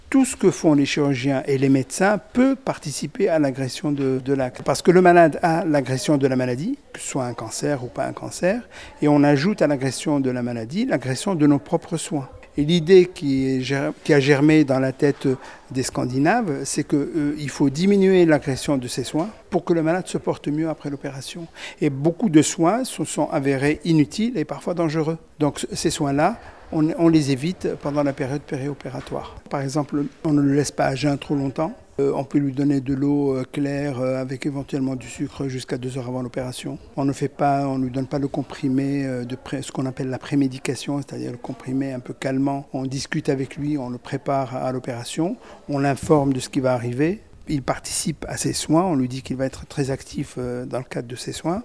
Interviews colloque RAC